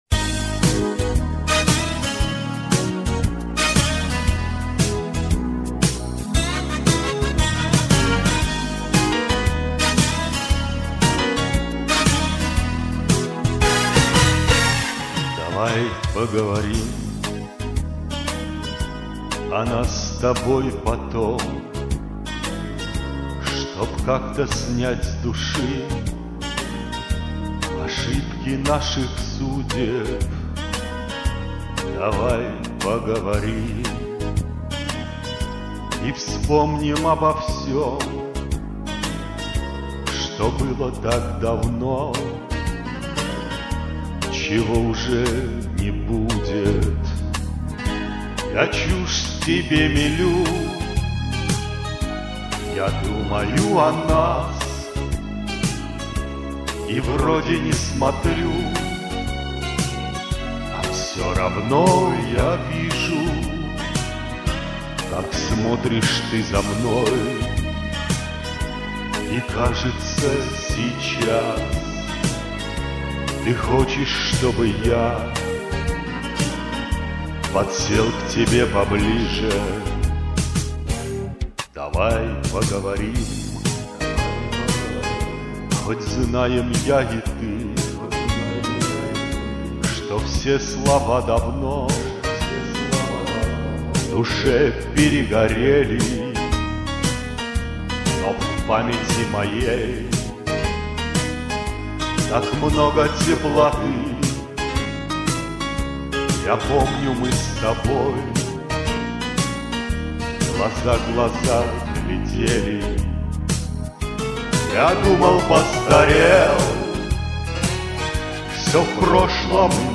Комментарий инициатора: Это семейный дуэт.